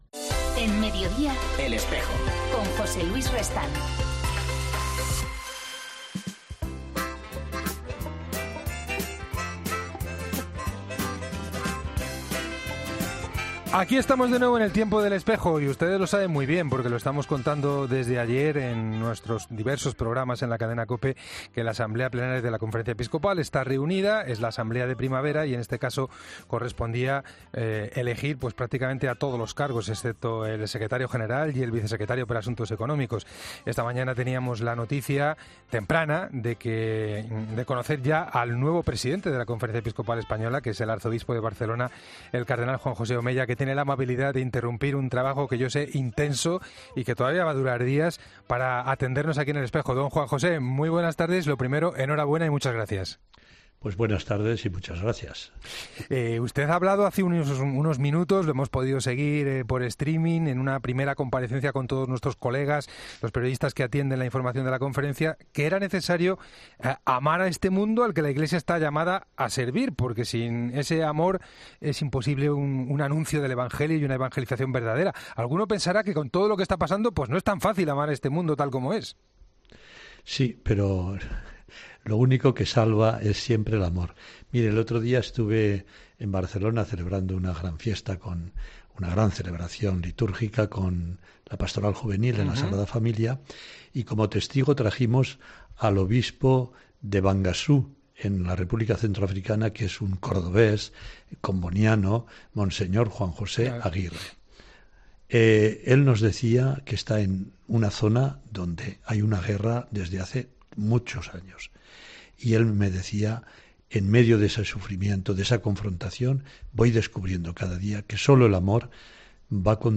El nuevo presidente de la Conferencia Episcopal española elige "El Espejo" para su primera entrevista tras su nombramiento